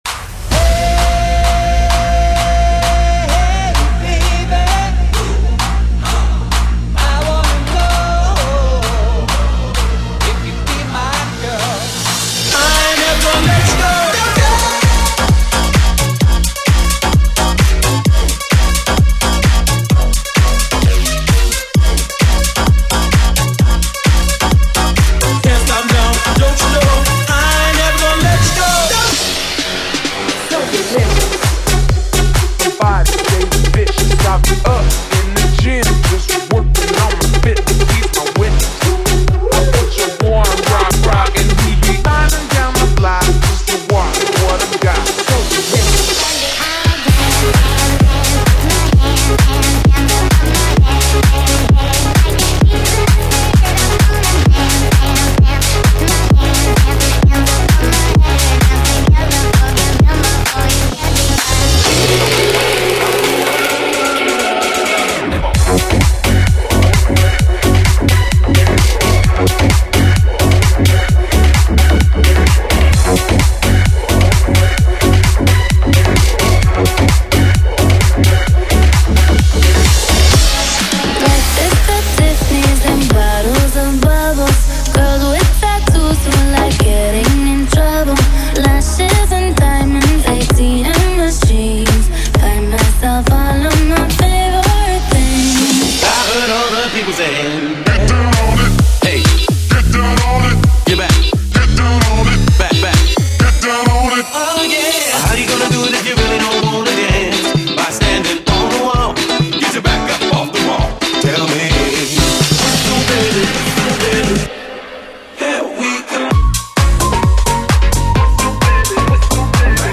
BPM: 130|140|150 (57:00)
Format: 32COUNT
New Radio, Throwbacks & Club!